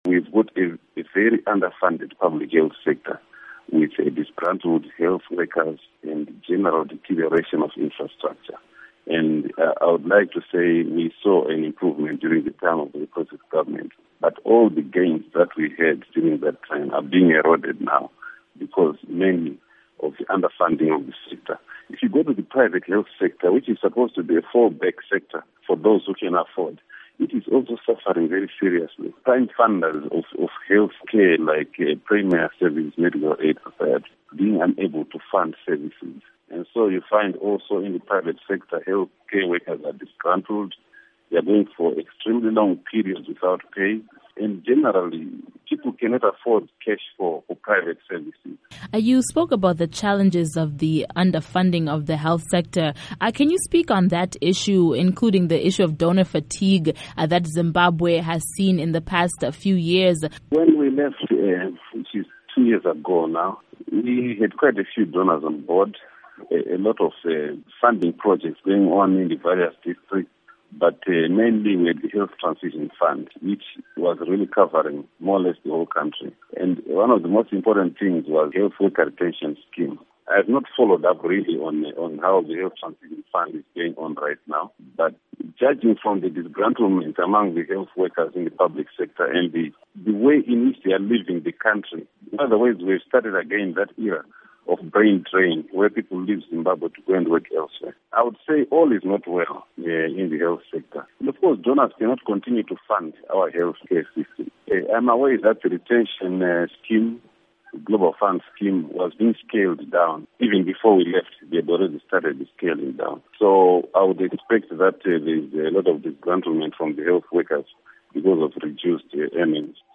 Interview With Henry Madzorera